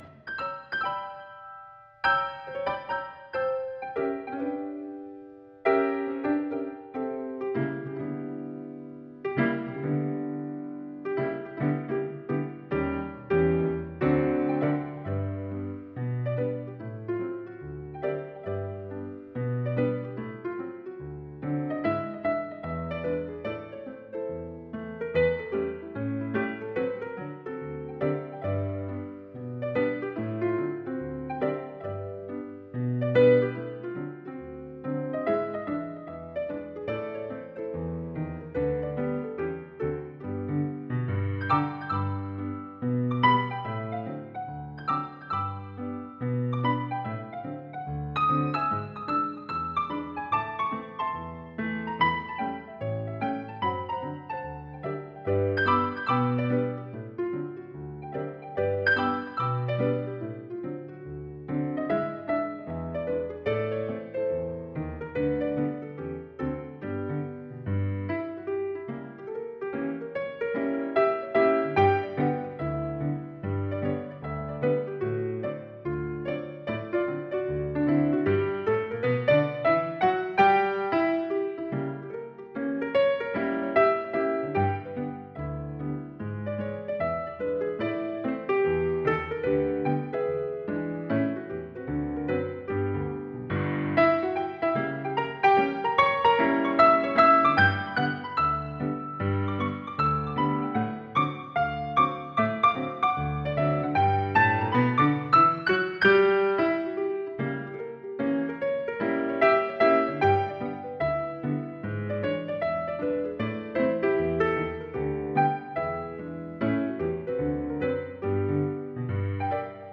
réorchestrés et entièrement libres de droit.